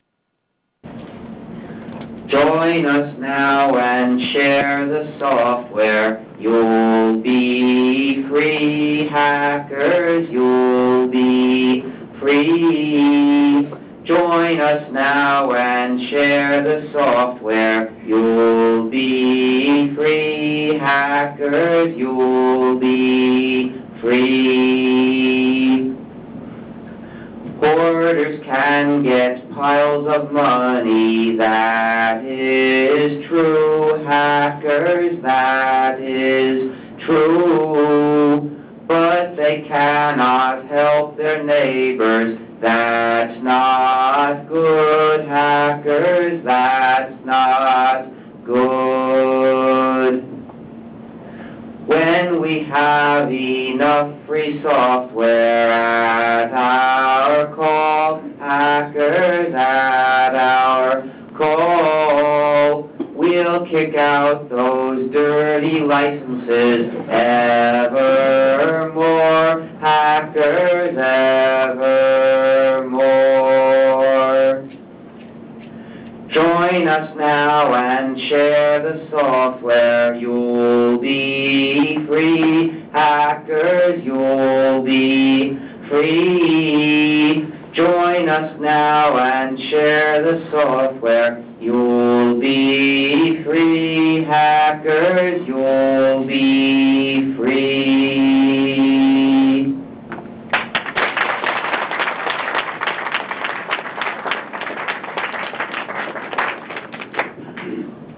The rather famous hacker RMS (Richard Stallman) is also known as a singer.